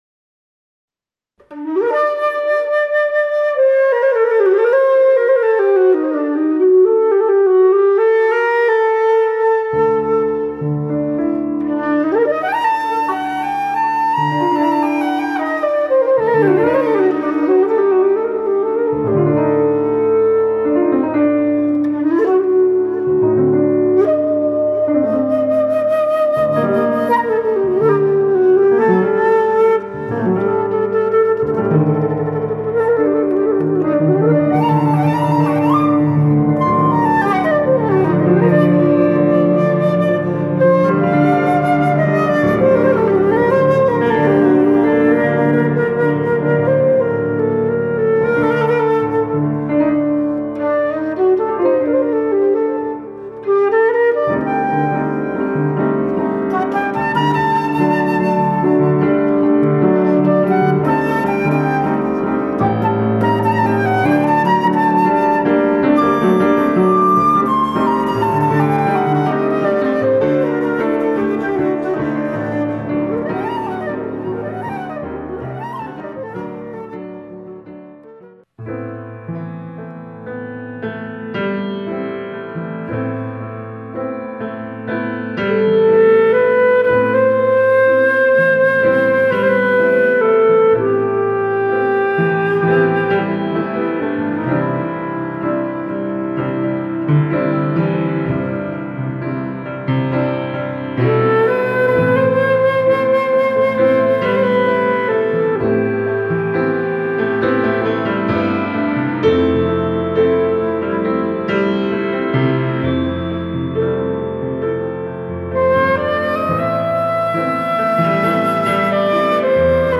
Improvisationen für Querflöte und Klavier